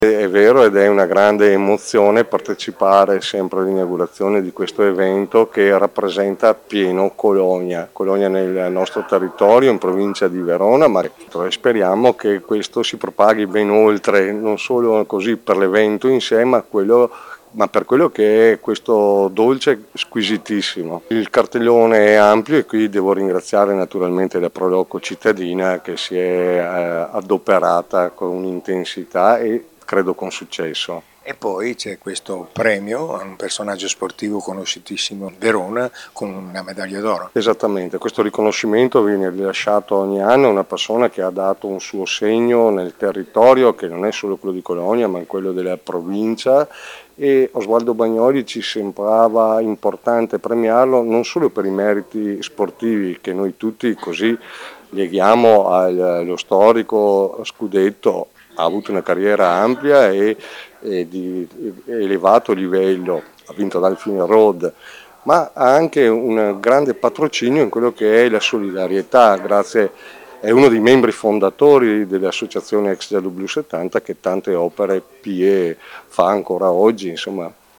Manuel Scalzotto presidente della Provincia di Verona e sindaco di Cologna Veneta: